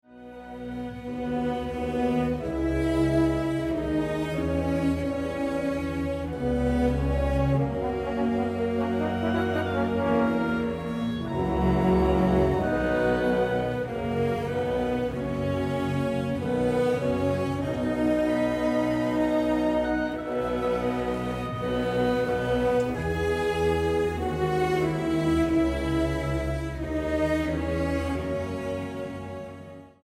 ópera en 4 actos